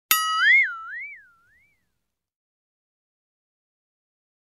Metal Twinning Sound Effect Download: Instant Soundboard Button